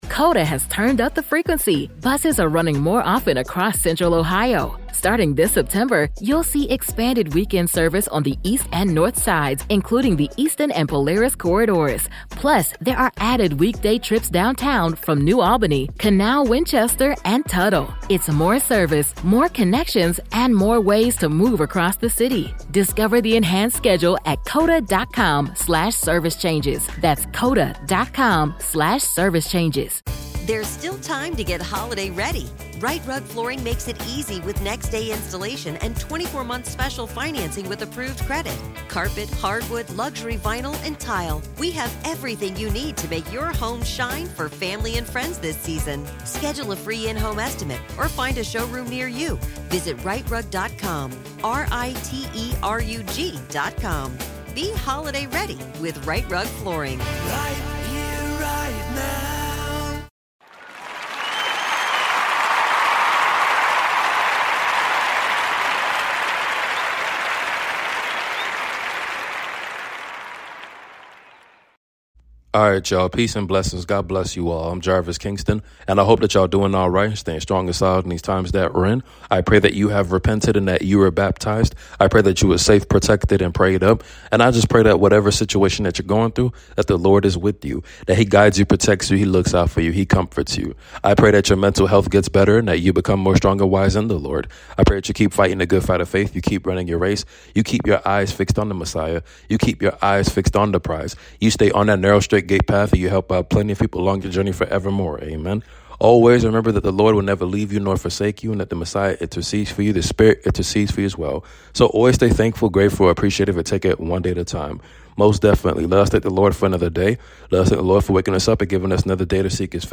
Your Nightly Prayer 🙏🏾 Ecclesiastes 3:1 & 6